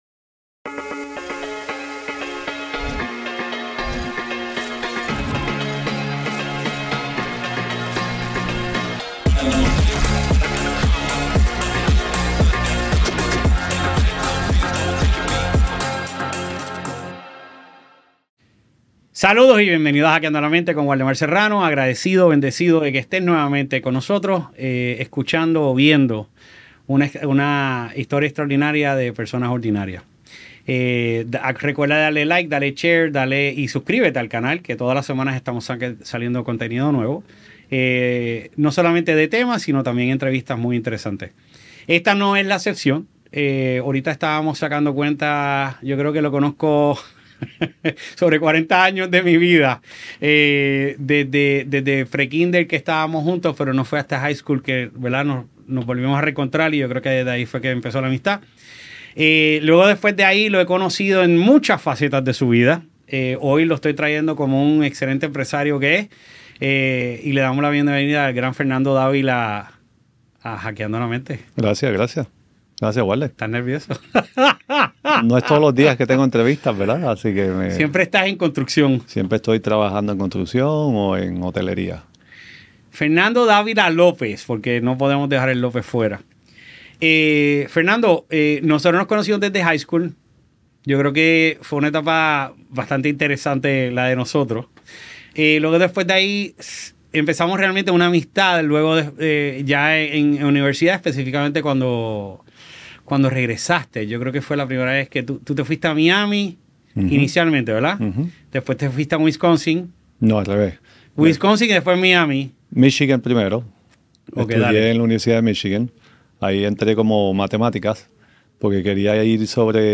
A lo largo de esta entrevista, exploraremos los desafíos y aprendizajes que ha enfrentado en su camino.